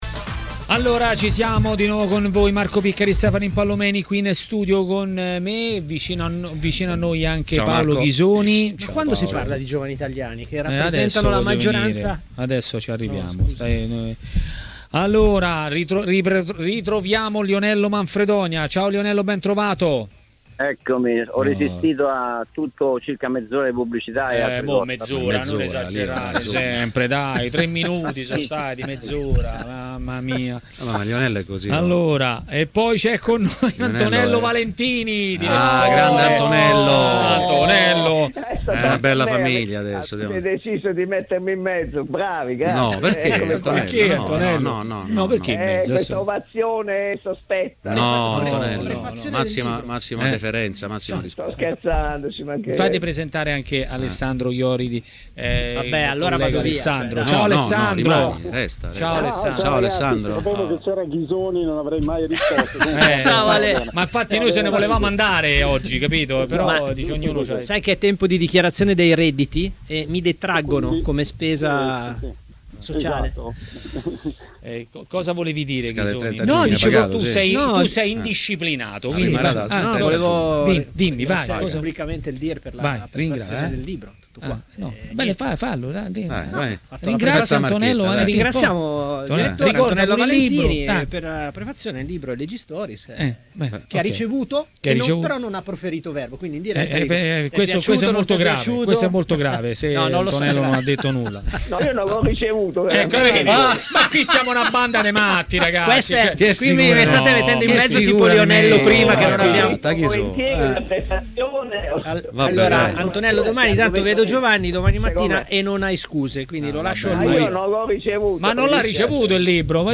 A intervenire in diretta a TMW Radio , durante Maracanà